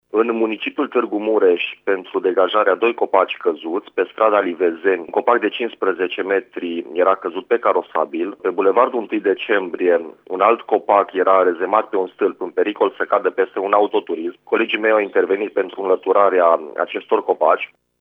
stiri-26-iun-ISU-copaci.mp3